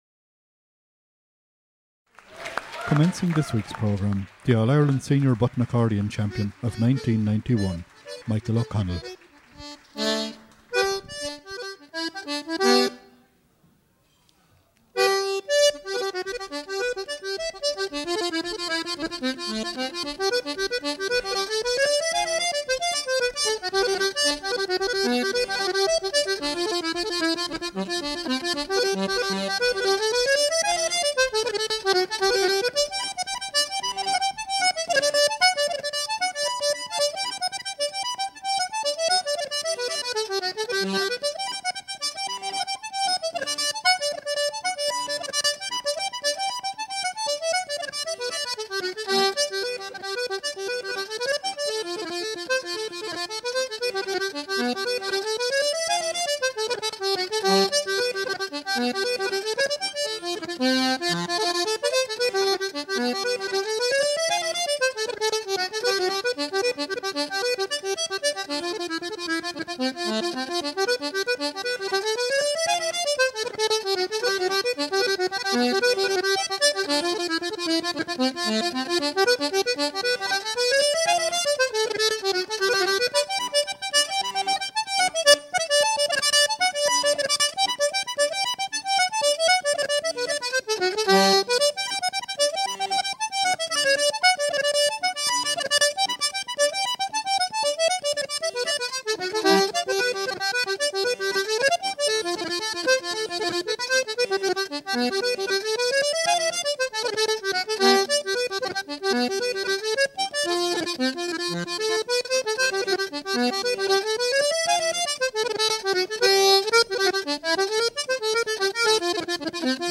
Button Accordion
reels, commencing with "The Bunch of Keys".
50th Anniversary Concert